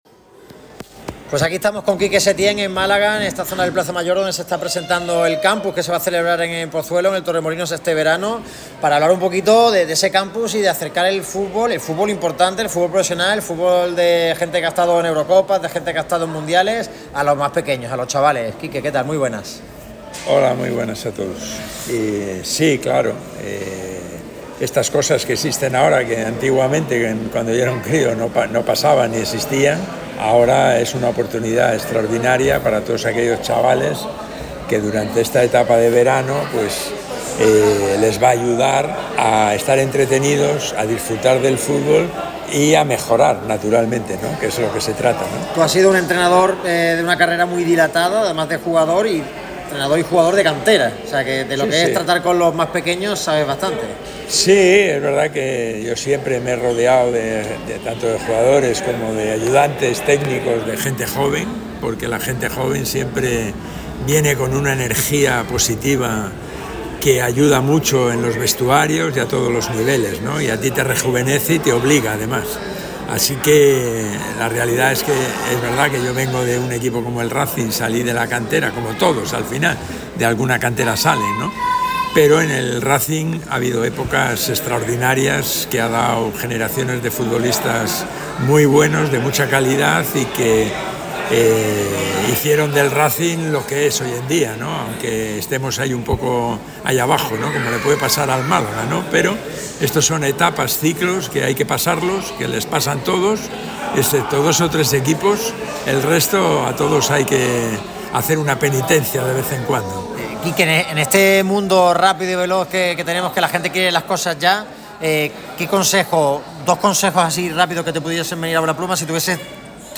Radio MARCA Málaga ha tenido el placer de entrevistar a varios de los protagonistas, que han dejado algunas pinceladas sobre el Málaga CF. Las leyendas de la selección, se mojan sobre el Málaga CF.